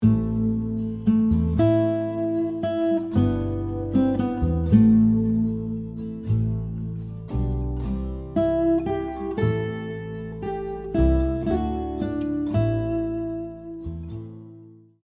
🎵 Melody with chords backing track
🎵 Full guitar arrangement backing track